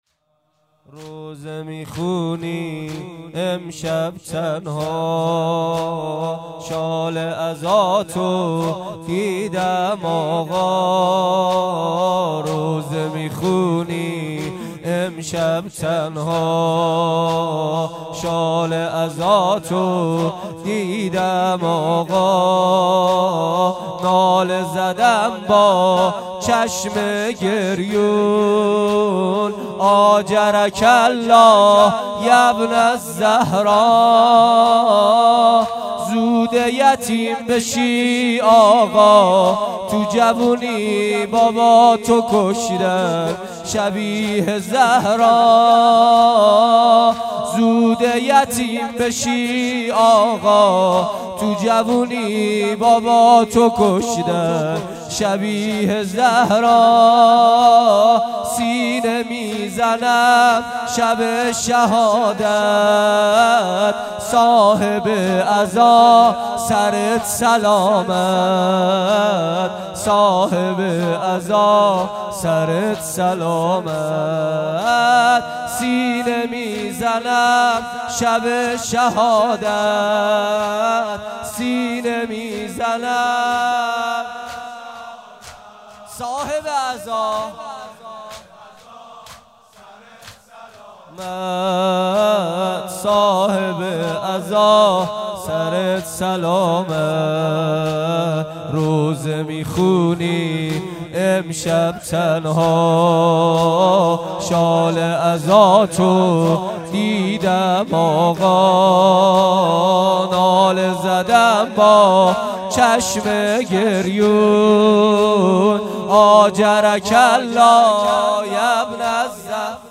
روضه می خونی امشب تنها | زمینه | کربلایی محمدحسین پویانفر